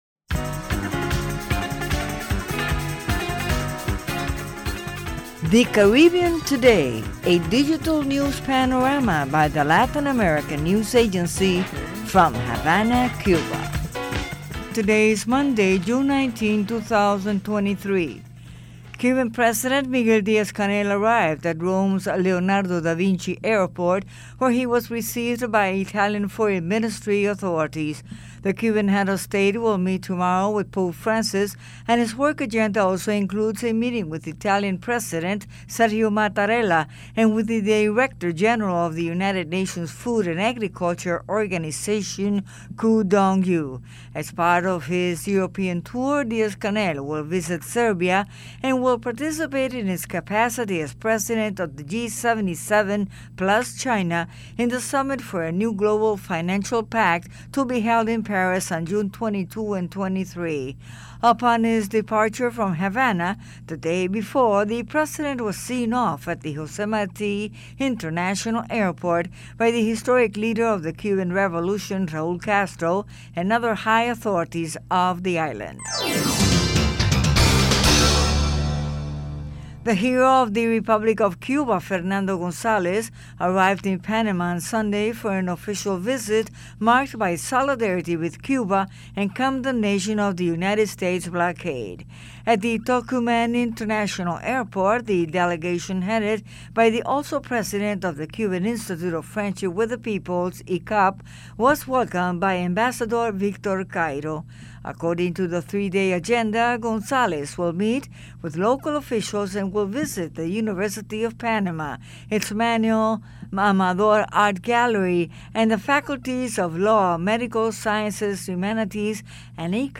June 19, news on the dot